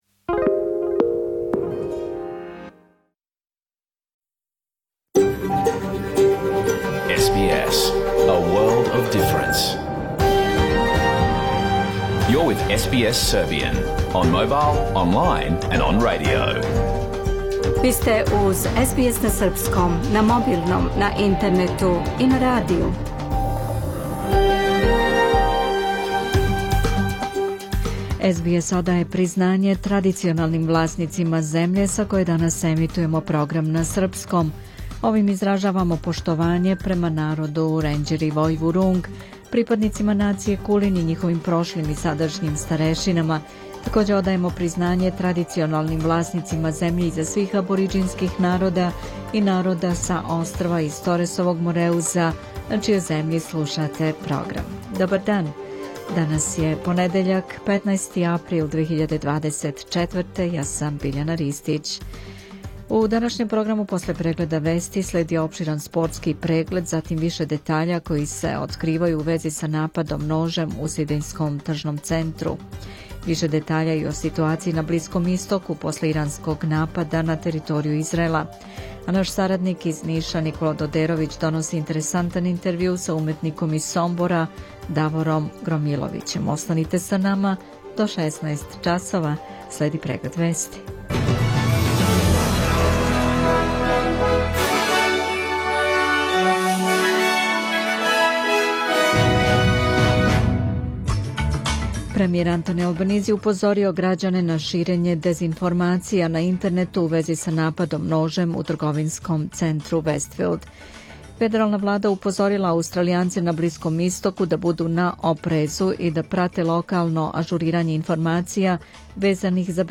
Програм емитован уживо 15. априла 2024. године
Уколико сте пропустили данашњу емисију, можете је послушати у целини као подкаст, без реклама.